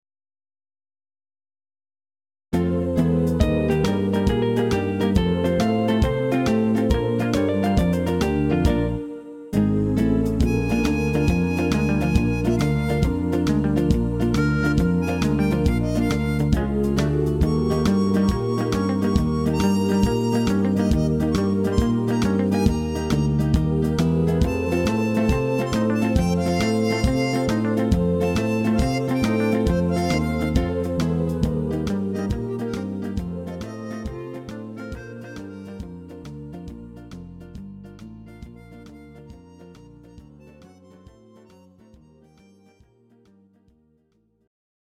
Rock n Roll & Twist